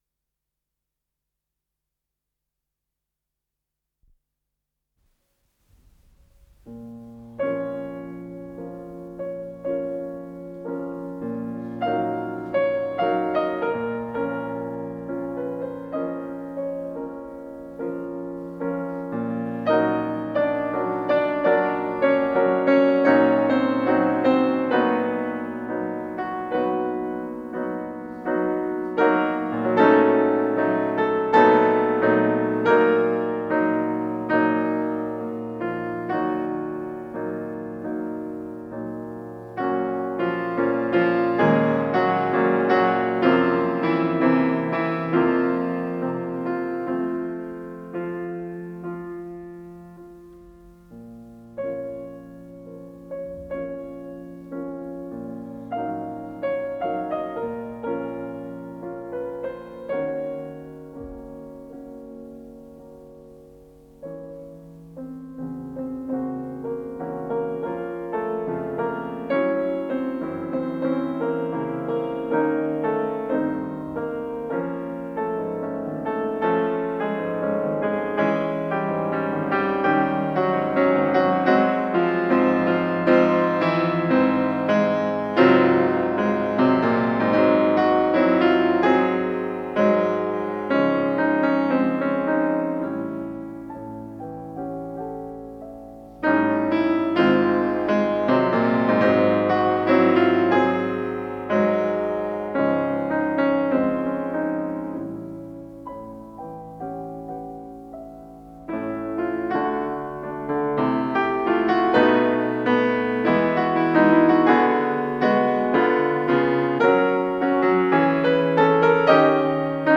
Анданте кантабиле, си бемоль минор
ИсполнителиВиктор Мержанов - фортепиано